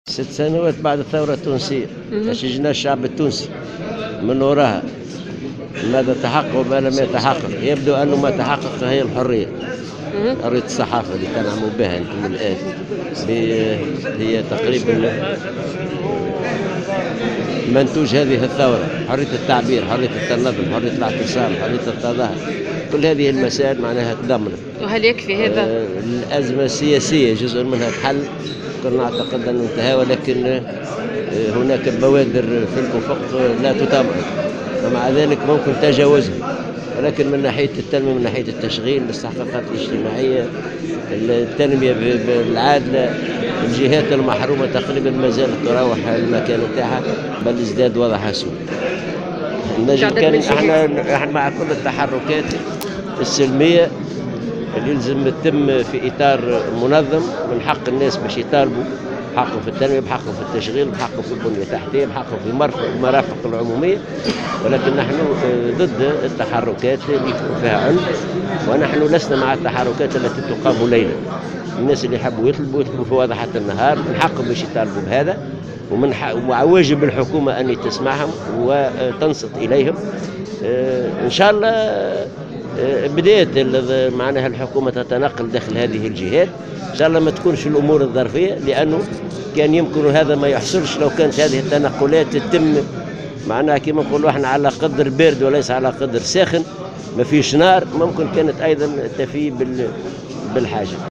وأكد العباسي، في تصريح لمراسلة الجوهرة أف أم، اليوم السبت، 14 جانفي 2017، أن المنظمة الشغيلة تساند التحركات الاحتجاجية المطالبة بالتنمية، شريطة أن تكون هذه التحركات التي تشهدها عدة مناطق تونسية حاليا، منظمة وسلمية، معبرا في الآن ذاته، عن رفض التحركات التي تقام ليلا.